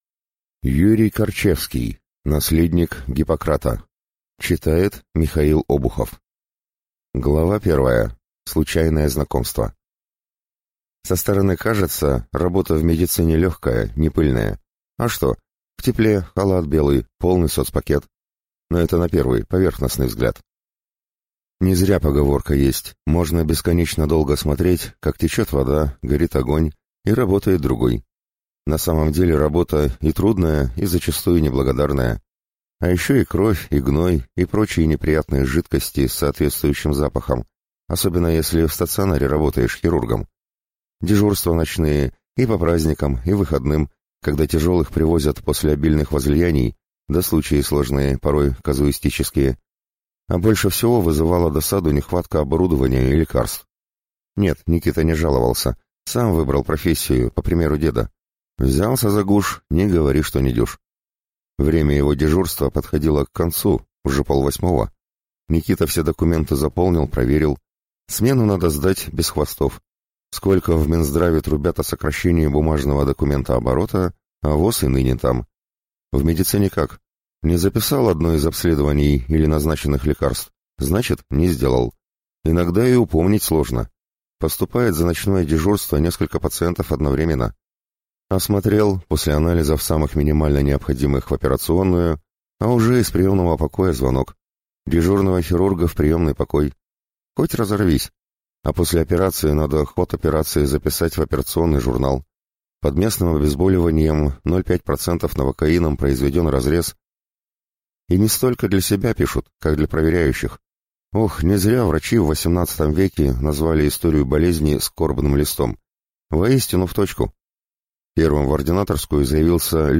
Аудиокнига Наследник Гиппократа | Библиотека аудиокниг